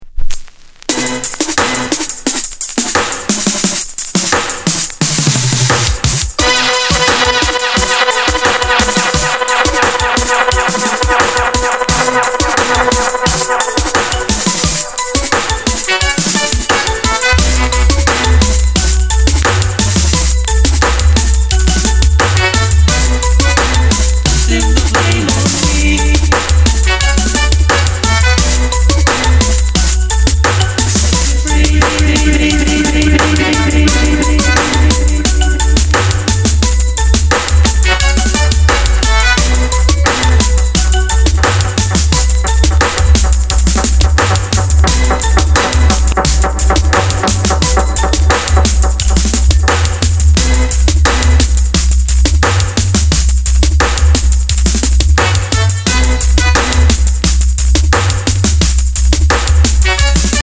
Genre: Reggae / Dancehall